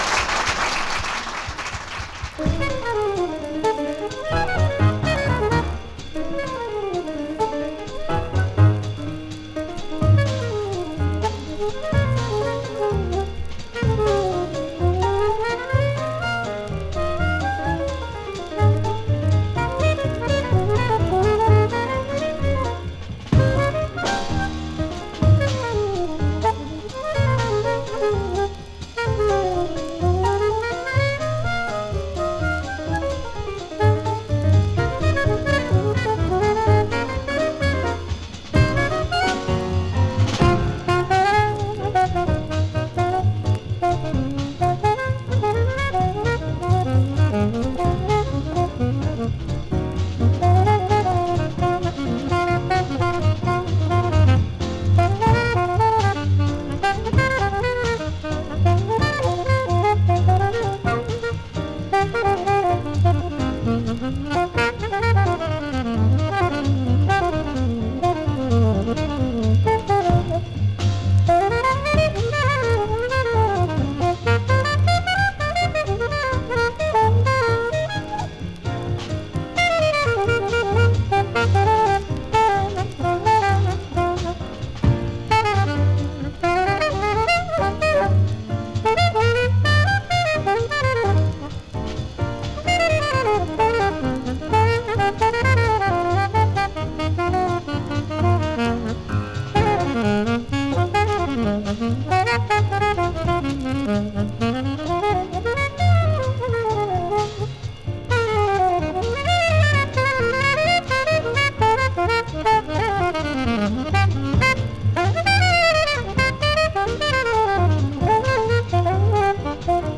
Recorded:  2 March, 1953 at Oberlin College, Oberlin, OH
Alto Sax
Piano
Bass
Drums